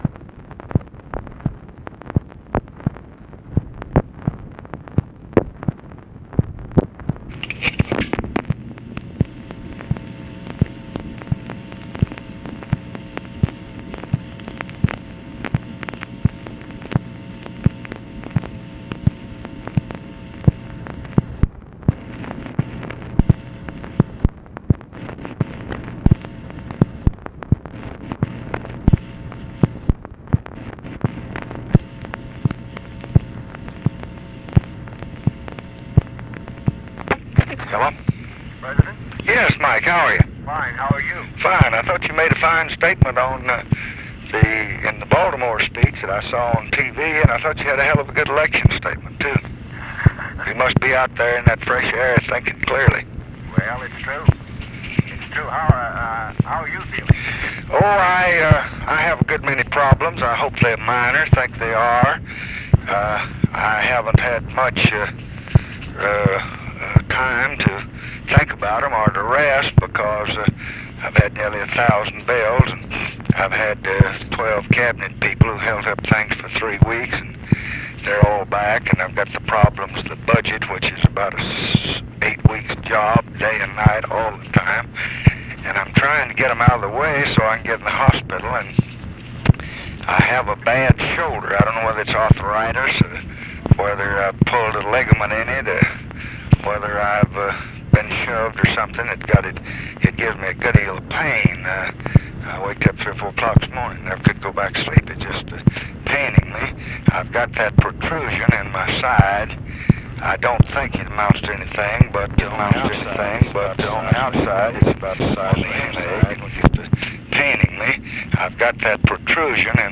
Conversation with MIKE MANSFIELD, November 11, 1966
Secret White House Tapes